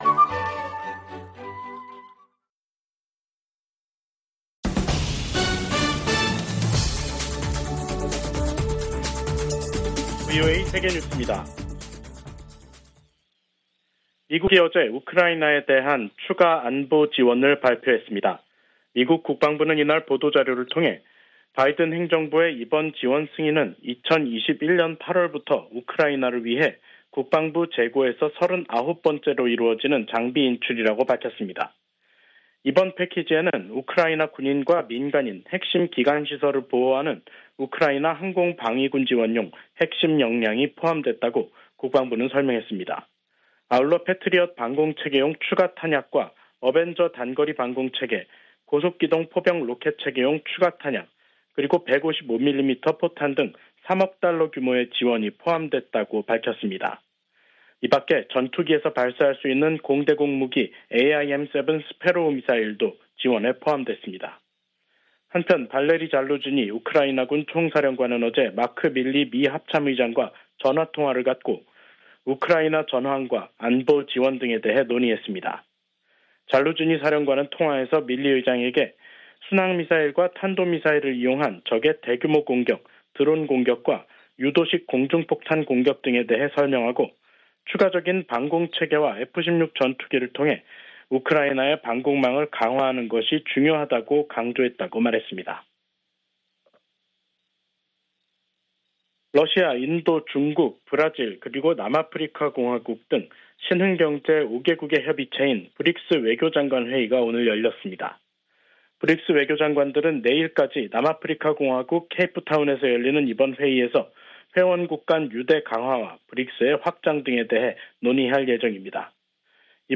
VOA 한국어 간판 뉴스 프로그램 '뉴스 투데이', 2023년 6월 1일 2부 방송입니다. 북한은 정찰위성 성공 여부와 관계 없이 군사 능력을 계속 발전시킬 것이라고 백악관 대변인이 말했습니다. 김여정 북한 노동당 부부장은 자신들의 군사정찰위성 발사를 규탄한 미국을 비난하며 위성 발사를 계속 추진하겠다고 밝혔습니다. 우주의 평화적 이용을 논의하는 유엔 회의에서 북한의 정찰위성 발사를 규탄하는 목소리가 나왔습니다.